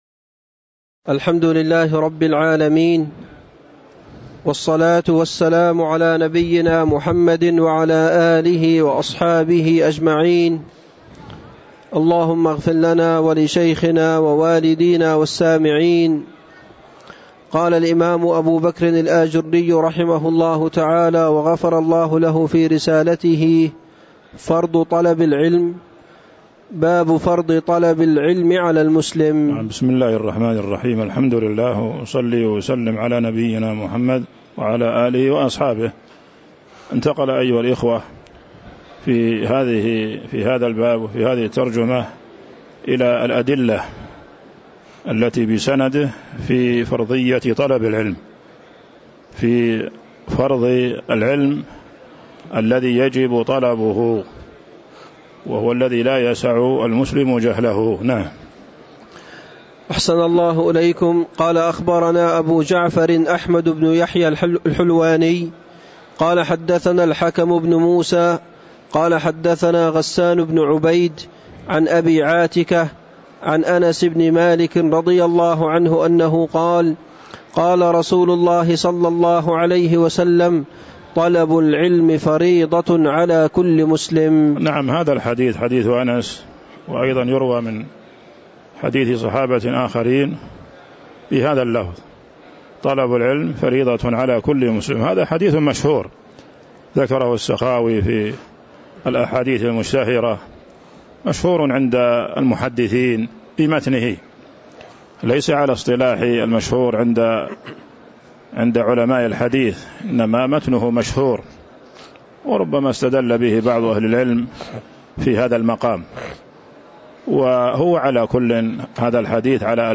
تاريخ النشر ١٧ ربيع الثاني ١٤٤٥ هـ المكان: المسجد النبوي الشيخ